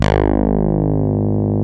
ODSSY BS 1.wav